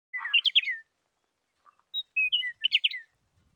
鳥の声は高周波数が多め
p-sound-chirp.mp3